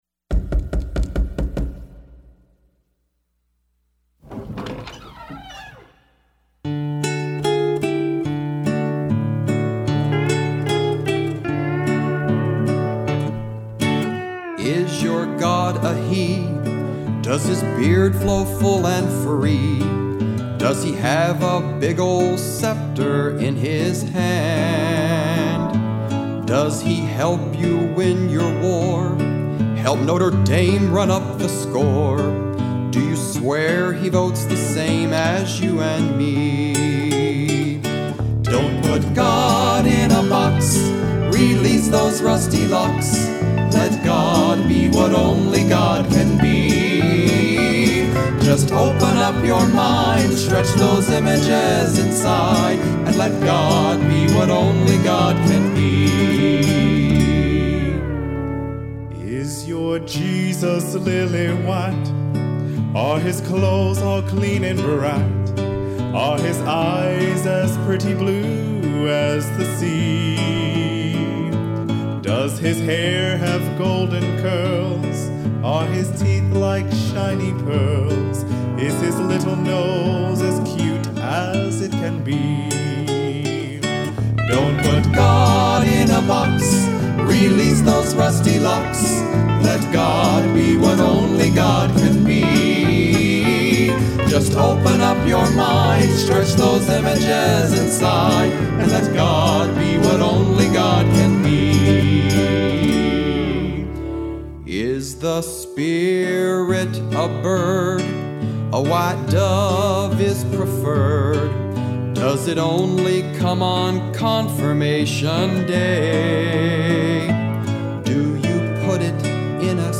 religious songs